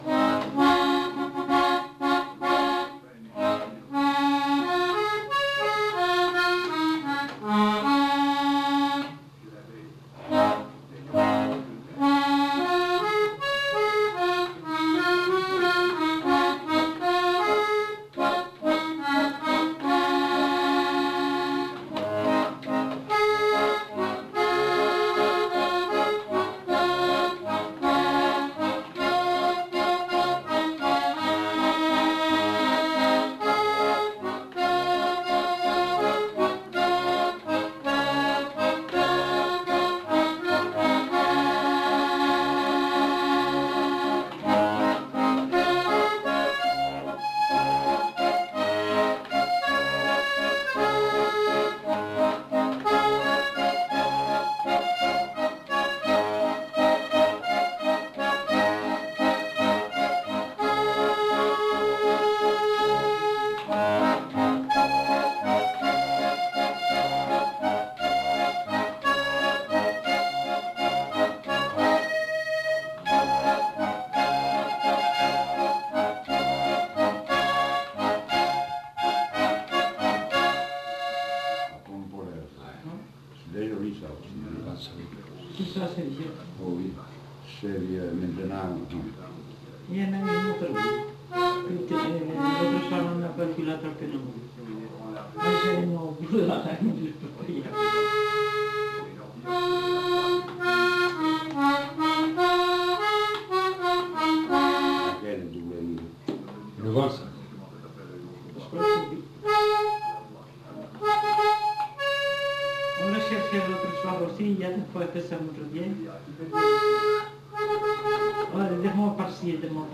Chants et musiques à danser de Bigorre interprétés à l'accordéon diatonique
enquêtes sonores